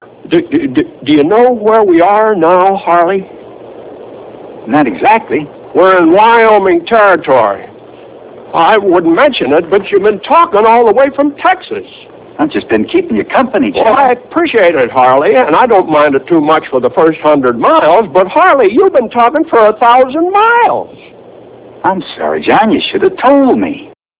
Talkin.real audio-26kb(John jimmy stewart) talkin to harley about him knowing where they are